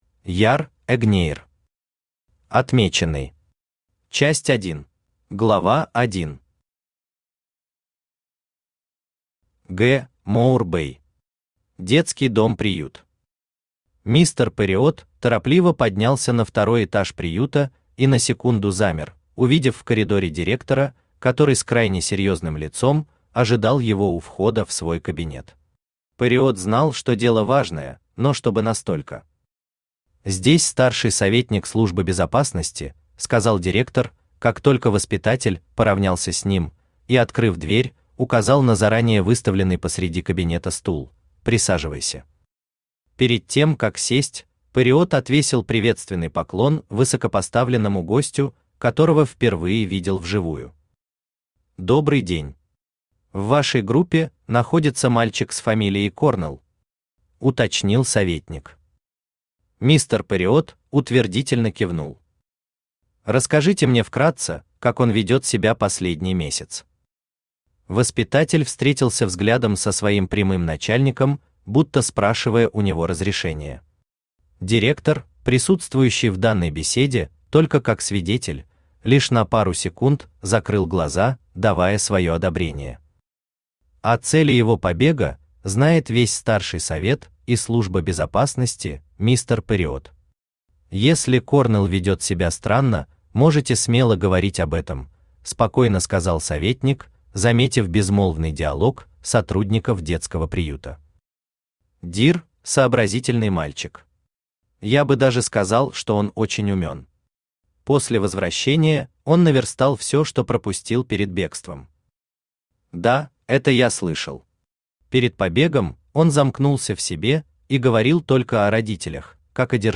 Аудиокнига Отмеченный. Часть 1 | Библиотека аудиокниг